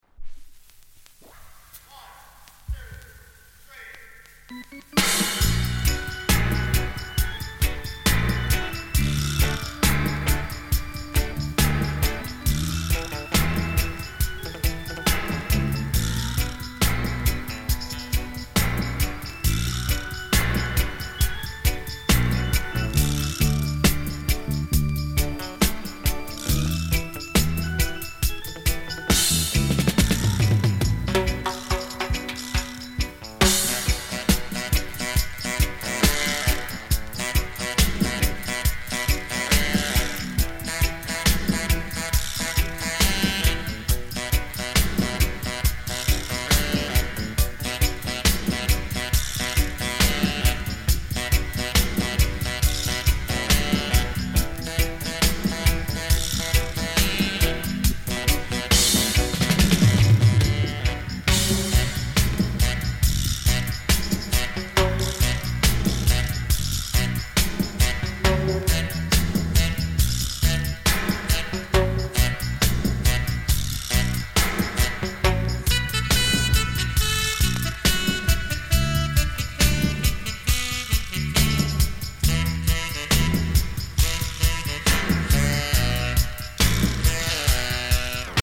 DUB, セサミストリート *
多少 ヒス・ノイズ 乗りますが、曲の間はほぼ気になりません。